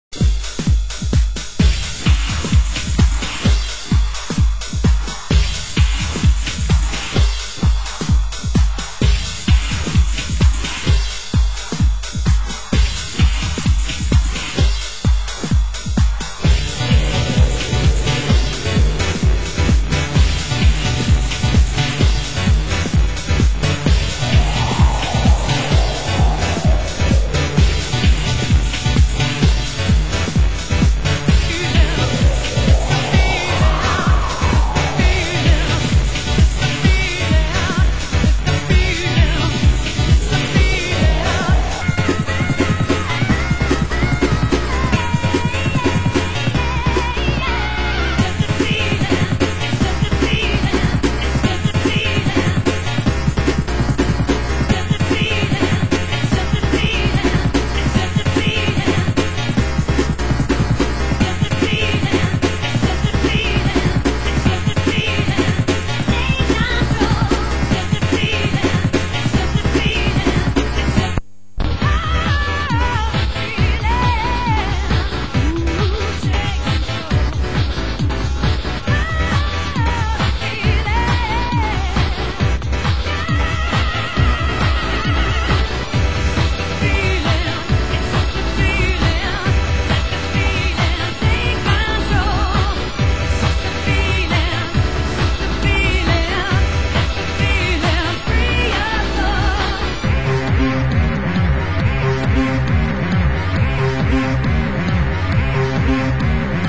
Genre Hardcore